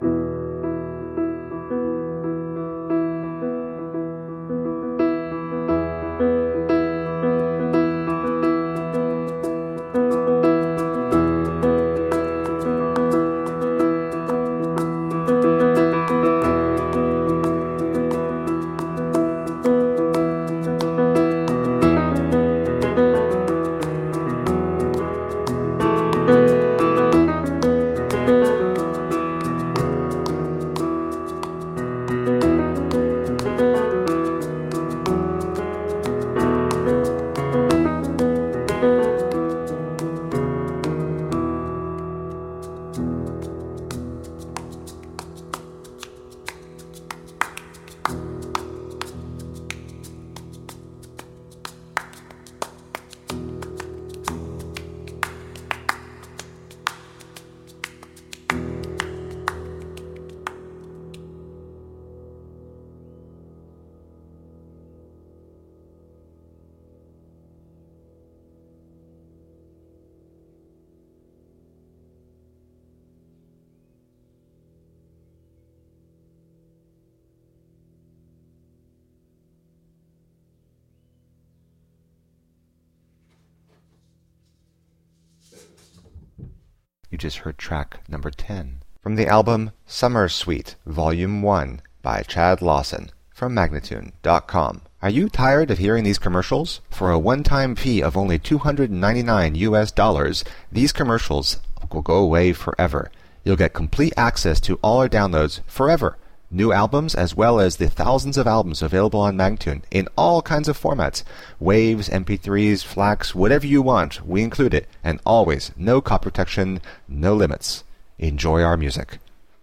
solo piano album
Entirely improvised in a lone 31 minute session